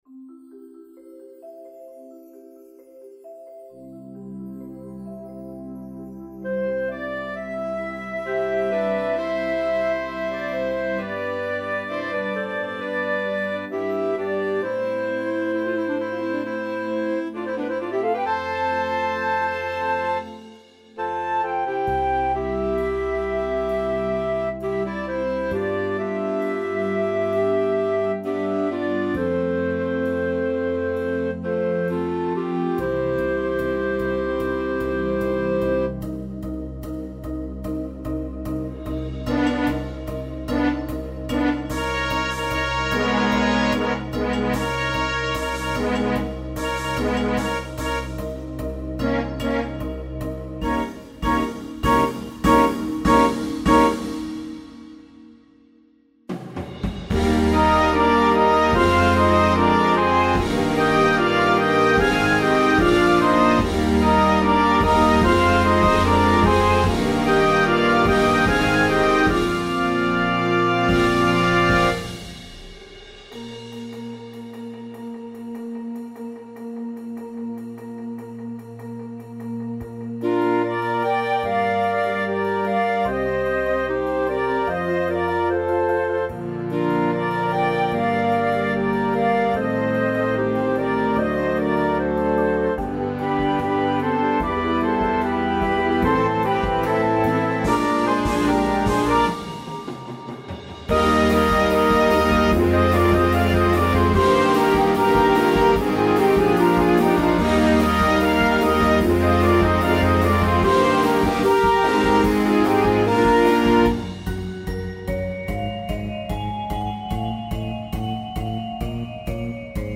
See it Live!